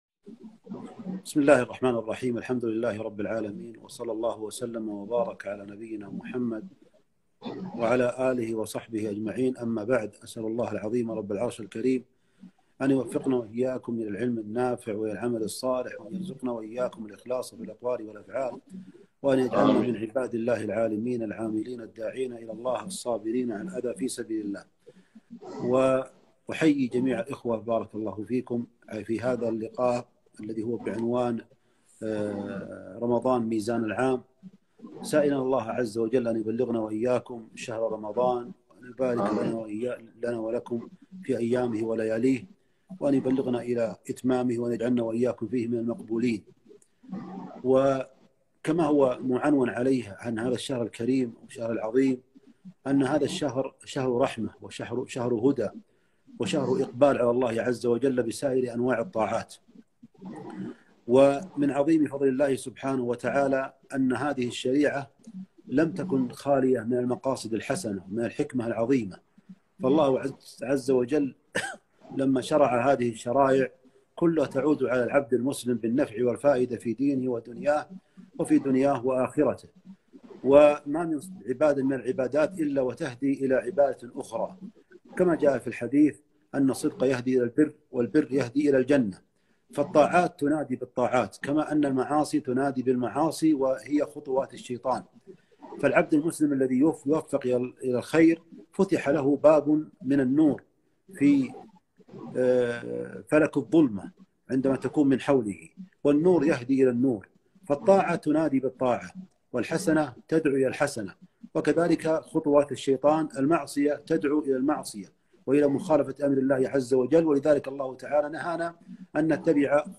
كلمة - رمضان ميزان العام